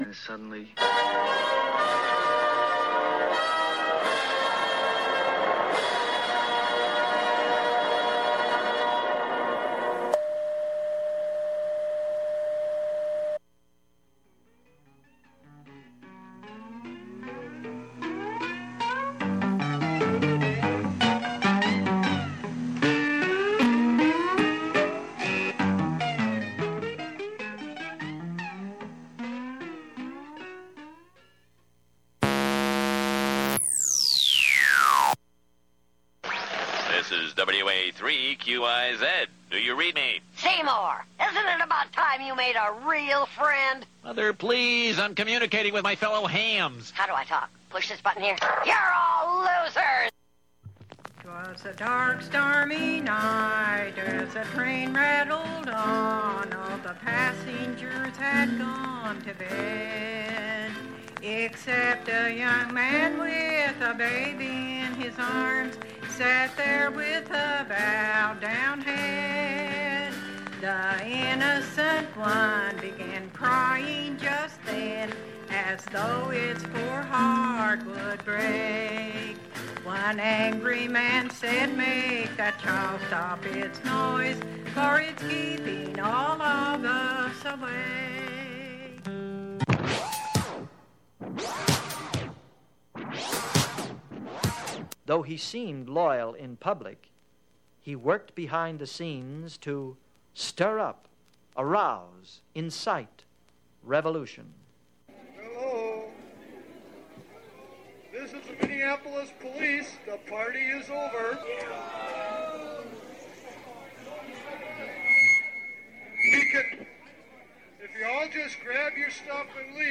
(46:58) Stingers are short, interstitial sound files used in radio, in this case assembled in an hour-long collage.